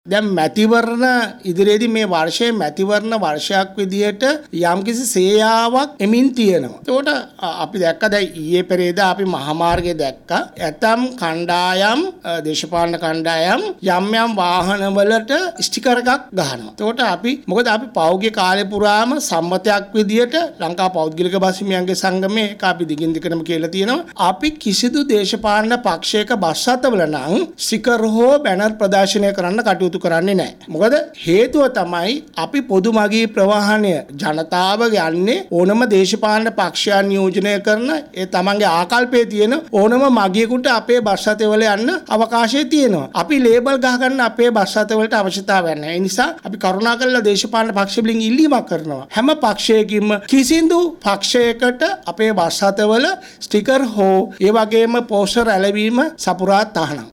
කොළඹ දී මාධ්‍ය හමුවක් කැඳවමින් ඒ මහතා මේ බව සඳහන් කළා.